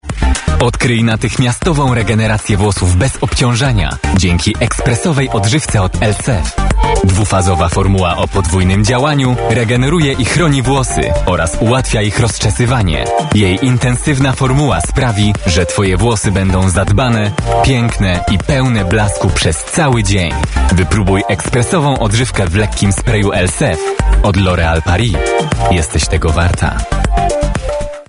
Owner of a pleasant, colorful and energetic voice that works well for announcements, commercials, narration and audiobooks.
Spot reklamowy
Male voice